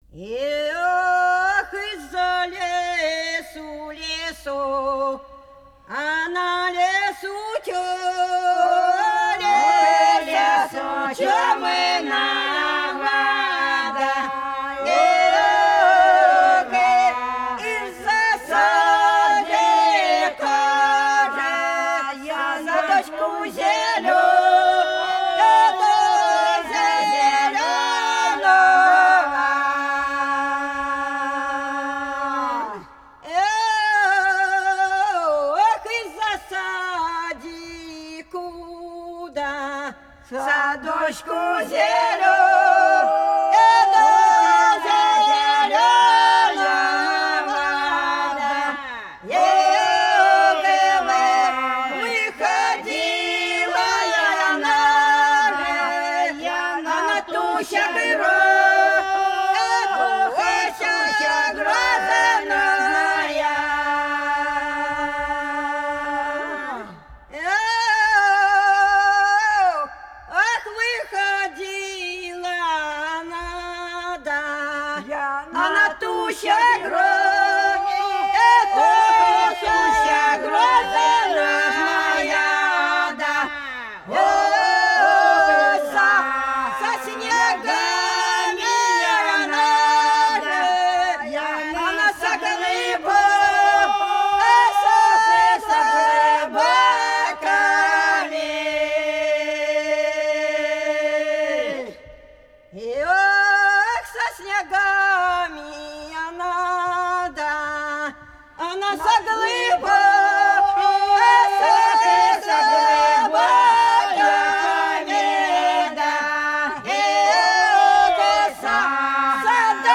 Пролетели все наши года Из-за лесу, лесу темного – протяжная, приуроченная к свадьбе (Фольклорный ансамбль села Пчелиновка Воронежской области)
25_Из-за_лесу,_лесу_темного_–_протяжная,_приуроченная_к_свадьбе.mp3